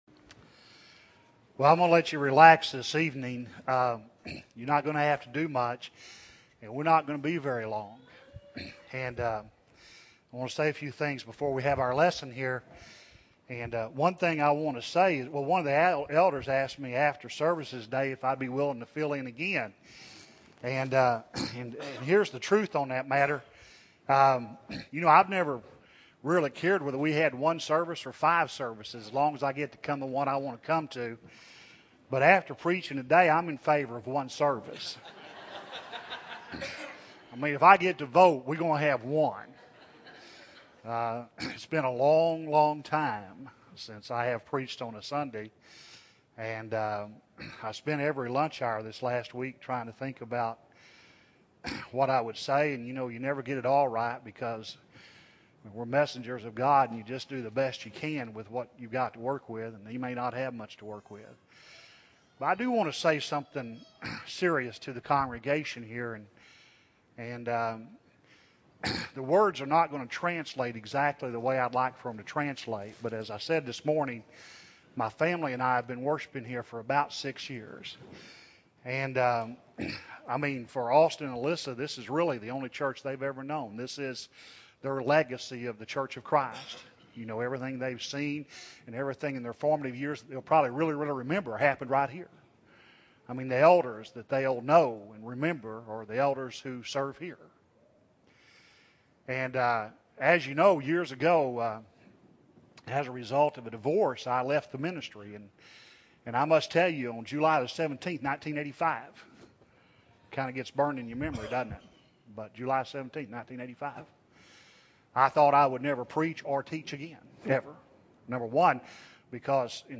2008-08-10 – Sunday PM Sermon – Bible Lesson Recording